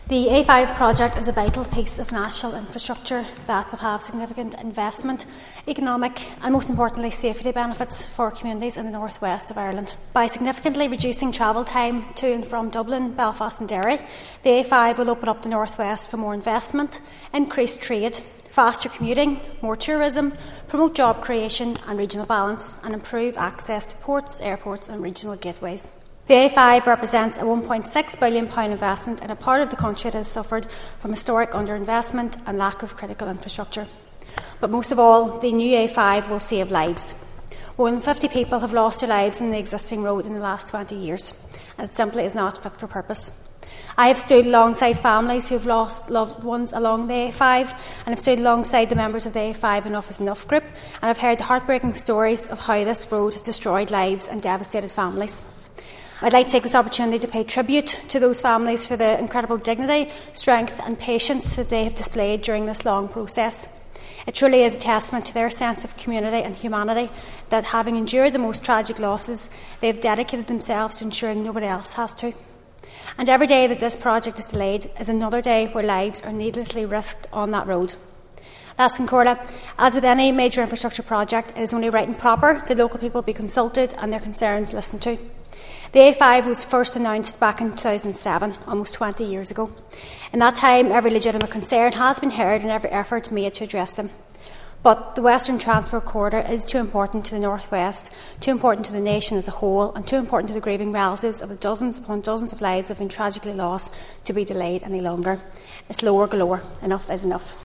That’s according to West Tyrone MLA Nicola Brogan, who told the Northern Ireland Assembly that while the £1.5 billion investment will be significant for the region, which has suffered years of underinvestment, she says it will ultimately save lives.
Ms Brogan says there must be an end to the delays in the works once and for all: